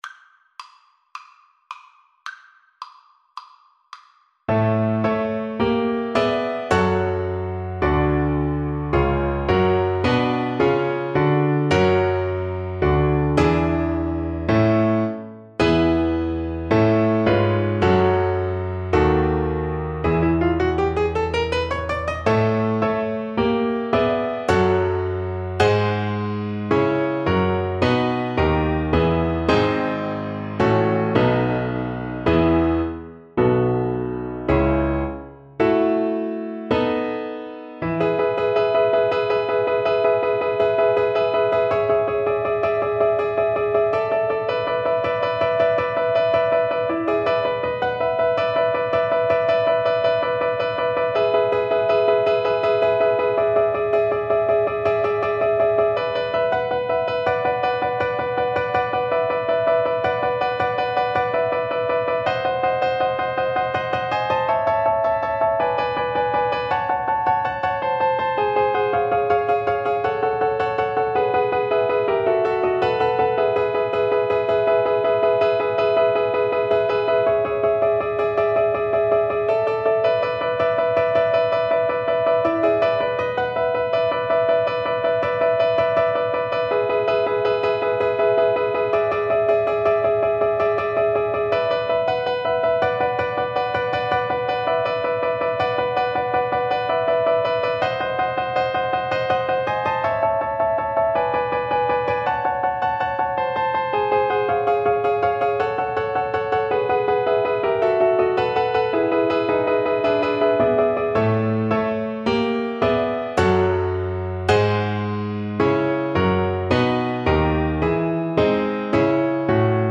Play (or use space bar on your keyboard) Pause Music Playalong - Piano Accompaniment Playalong Band Accompaniment not yet available transpose reset tempo print settings full screen
Violin
4/4 (View more 4/4 Music)
Allegro vivace = c. 144 (View more music marked Allegro)
A major (Sounding Pitch) (View more A major Music for Violin )
Classical (View more Classical Violin Music)